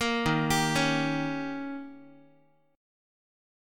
Listen to Eb7 strummed